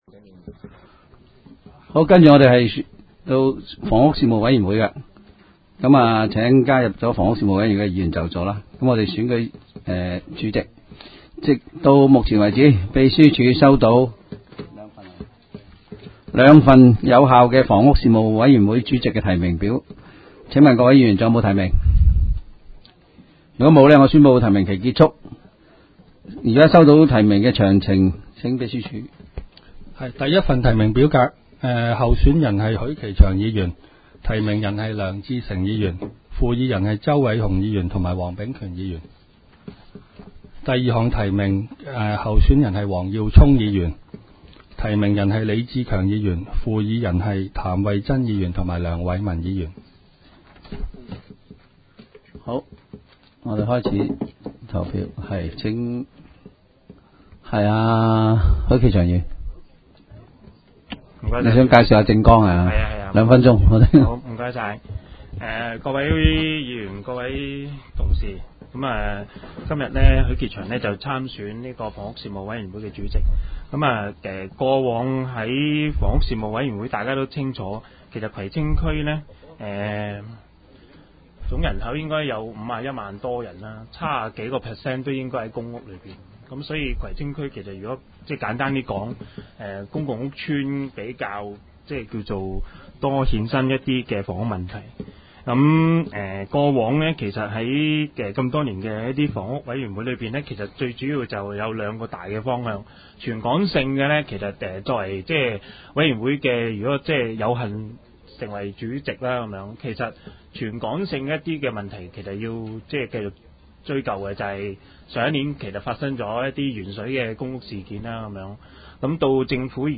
委员会会议的录音记录
房屋事务委员会第一次特别会议会议 日期: 2016-01-19 (星期二) 时间: 下午4时正 地点: 香港葵涌兴芳路166-174号 葵兴政府合署10楼 葵青民政事务处会议室 议程 讨论时间 开会词 00:07:33 1. 选举房屋事务委员会主席及副主席 00:19:19 全部展开 全部收回 议程: 开会词 讨论时间: 00:07:33 前一页 返回页首 议程:1.